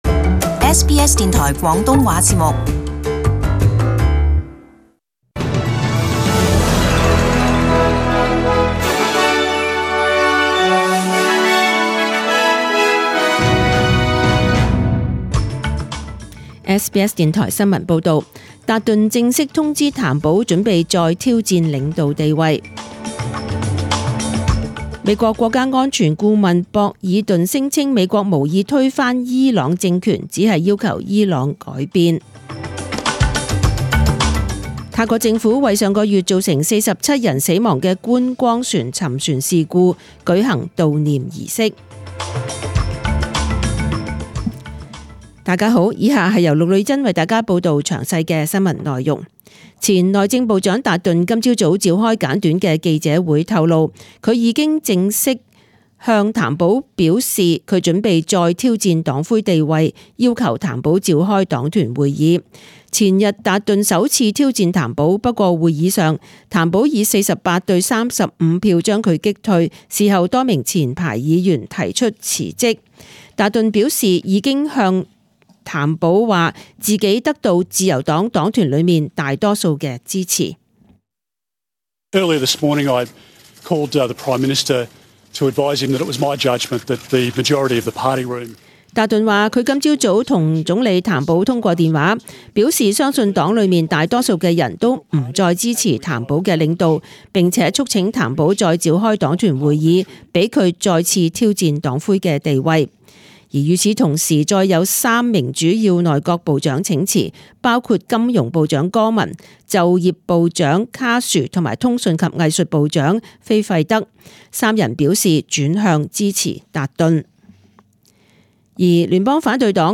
SBS中文新闻 （八月廿三日）
请收听本台为大家准备的详尽早晨新闻。